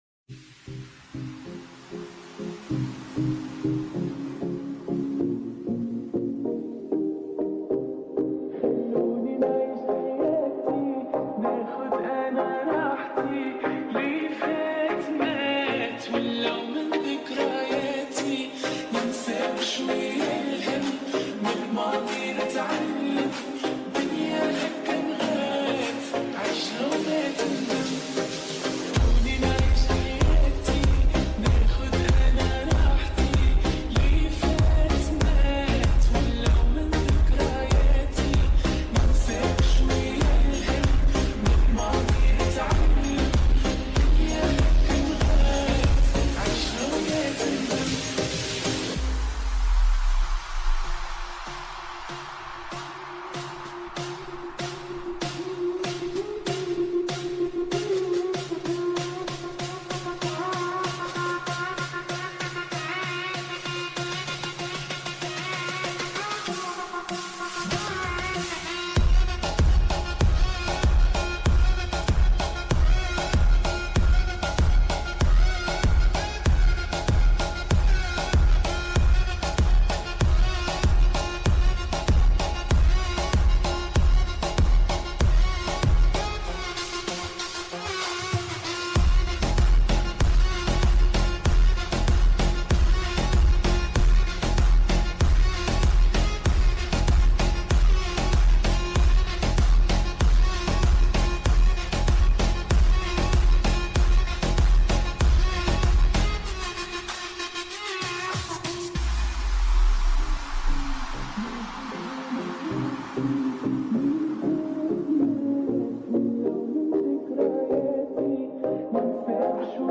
Slowed use headphones
Arabic song